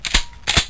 SHOTGNCK.wav